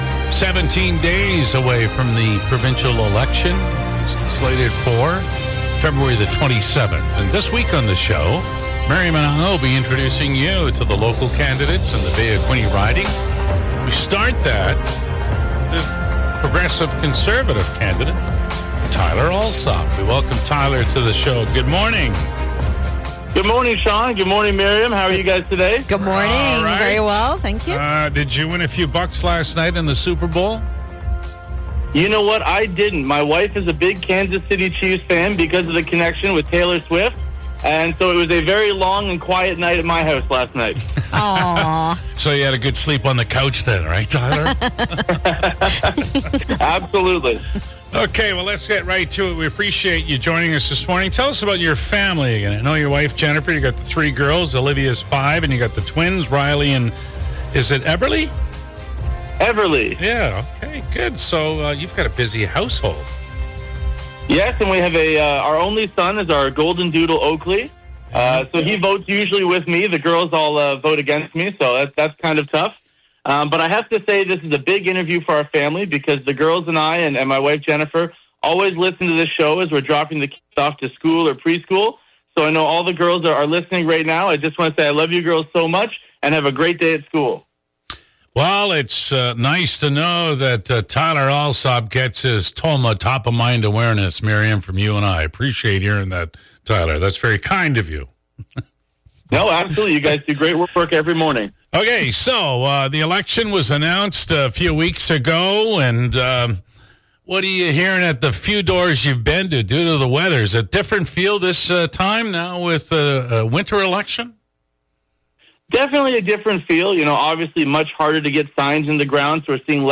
Ahead of the provincial election on February 27, the MIX Morning Crew organized a Q&A with each of the candidates for everyday of the week.